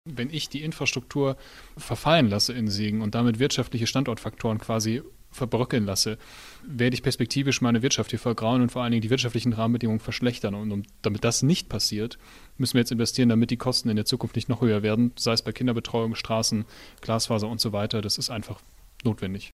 Im Radio Siegen-Interview rechtfertigte Tristan Vitt die hohen Investitionen auf Pump.